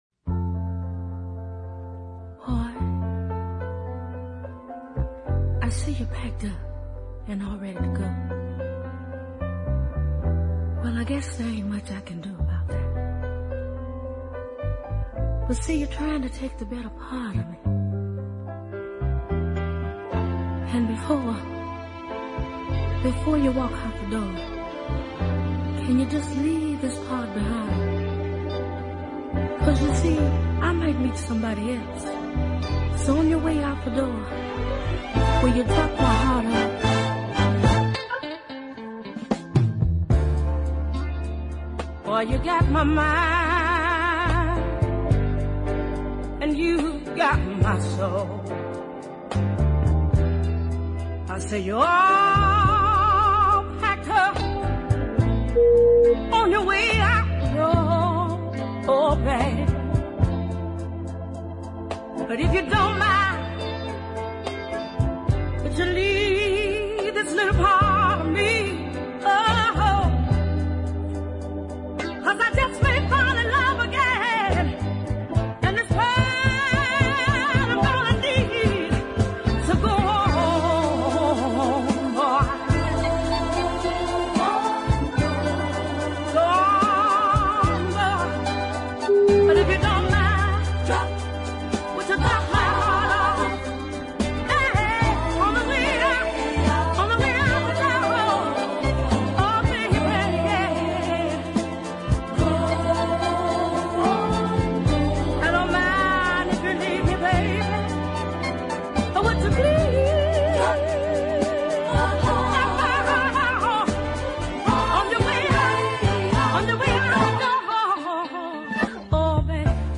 an emotionally charged tour de force.